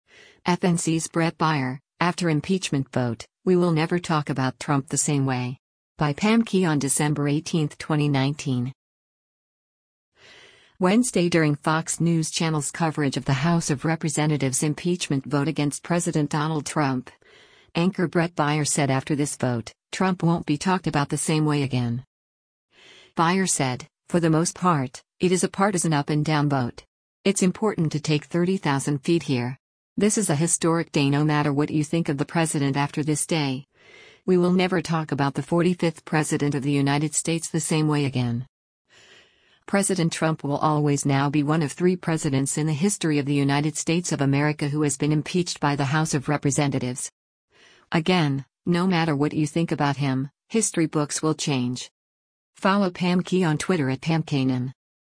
Wednesday during Fox News Channel’s coverage of the House of Representatives impeachment vote against President Donald Trump, anchor Bret Baier said after this vote, Trump won’t be talked about “the same way again.”